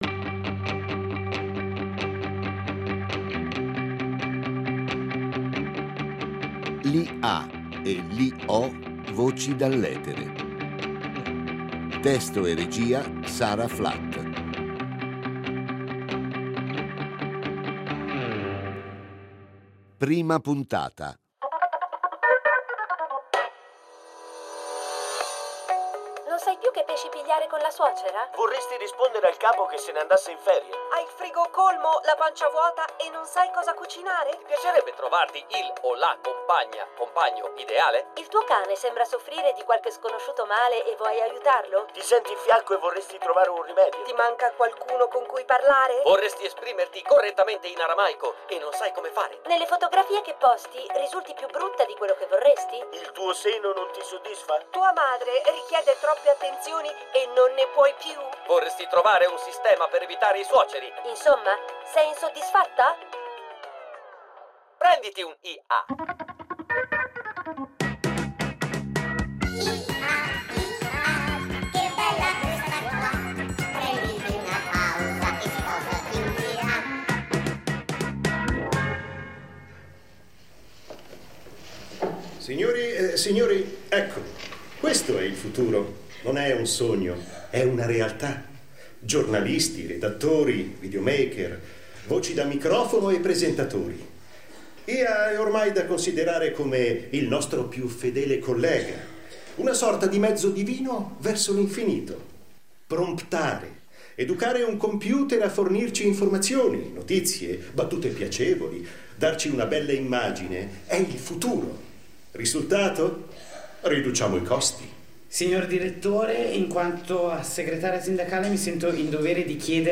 Domenica in scena L’Ai e l’IO: voci dall’etere (1./5) Ieri 38 min Contenuto audio Disponibile su Scarica Un’impresa spaziale, cercare di raccontare cosa sia e siano le intelligenze artificiali, un viaggio in un mondo dove i confini tra umano e artificiale si sfumano.